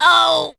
penny_hurt_vo_02.wav